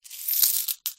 coins-in-hand-1.mp3